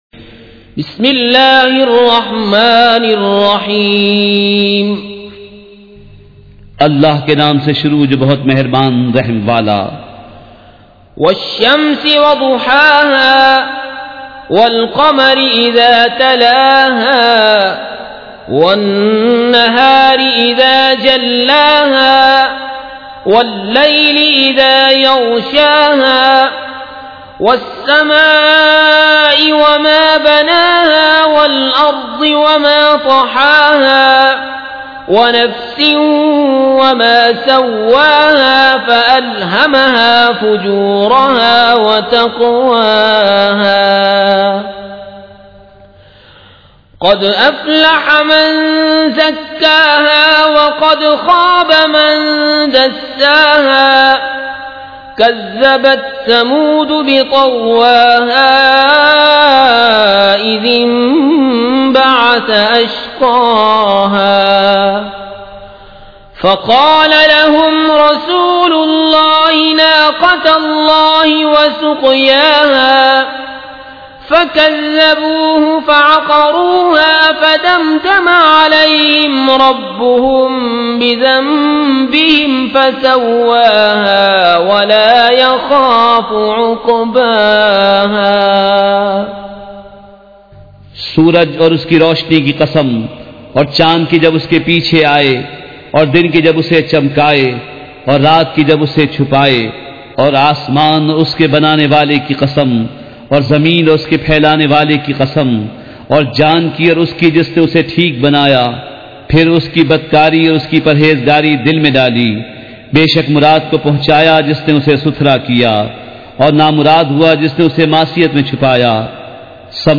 سورۃ الشّمس مع ترجمہ کنزالایمان ZiaeTaiba Audio میڈیا کی معلومات نام سورۃ الشّمس مع ترجمہ کنزالایمان موضوع تلاوت آواز دیگر زبان عربی کل نتائج 2083 قسم آڈیو ڈاؤن لوڈ MP 3 ڈاؤن لوڈ MP 4 متعلقہ تجویزوآراء